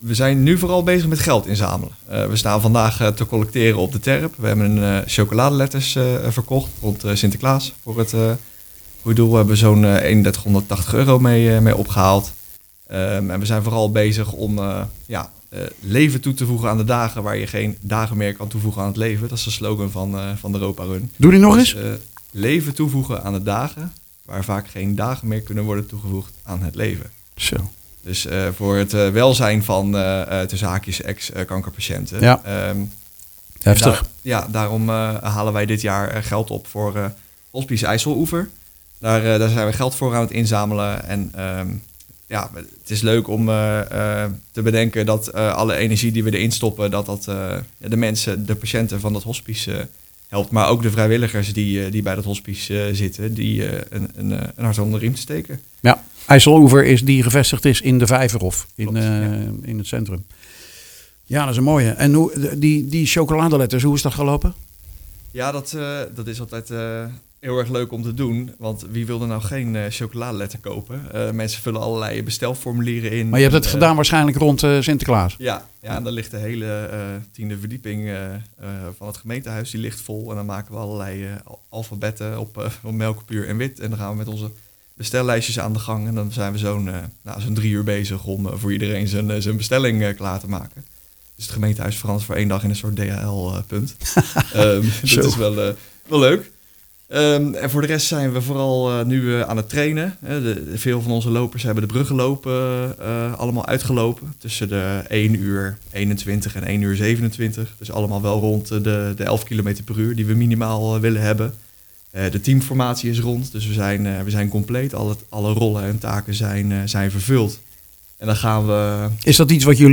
praat in de studio